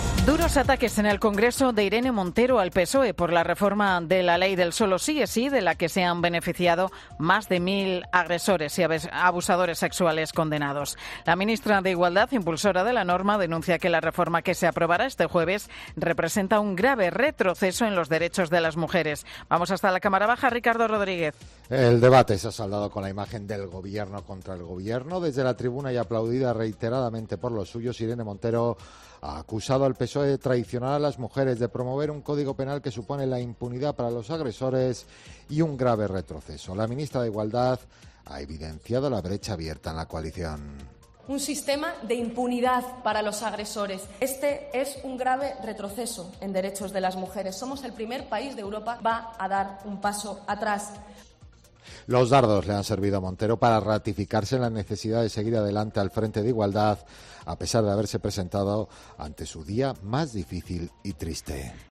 Desde la tribuna de oradores de la Cámara Baja y aplaudida reiteradamente por los suyos, Irene Montero ha acusado al PSOE de traicionar a las mujeres y de promover un Código Penal que supone la impunidad para los agresores y un grave retroceso.